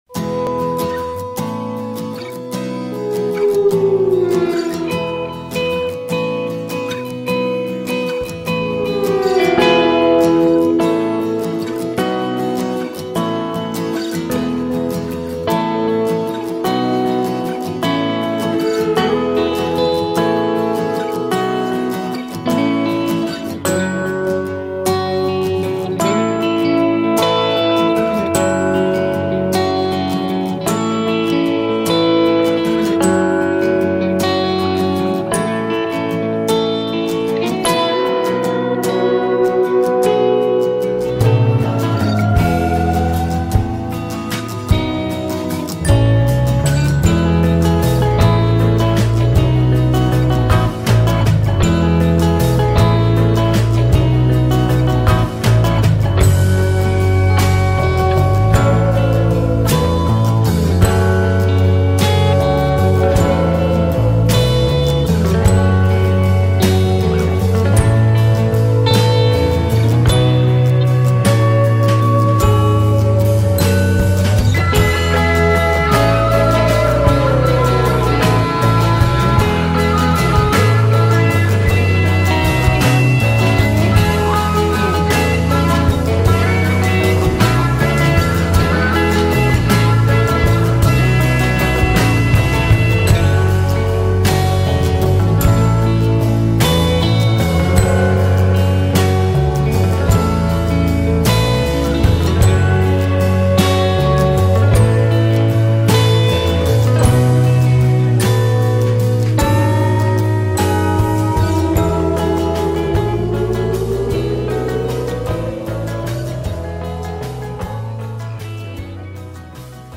it’s a sweet instrumental version